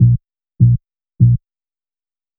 FK100BASS1-R.wav